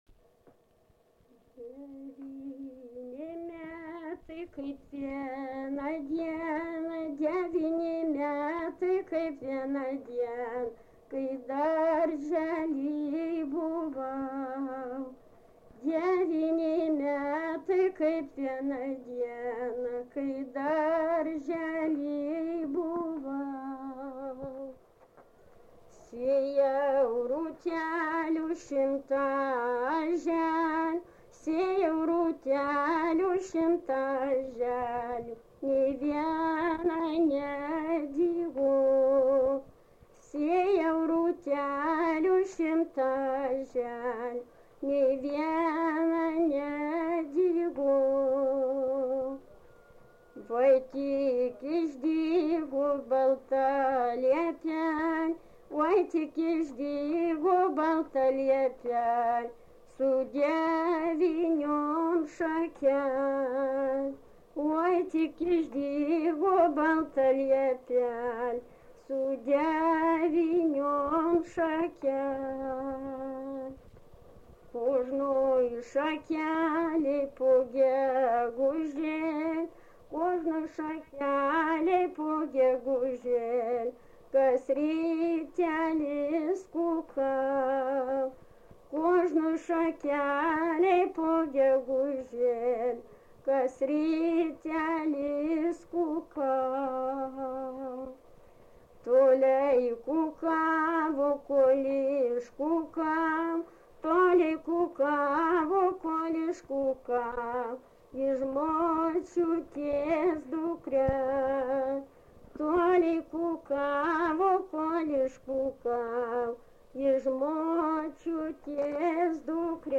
šokis
Erdvinė aprėptis Eišiškės Mečiūnai
Atlikimo pubūdis vokalinis
Dainuoja dvi dainininkės dviem balsais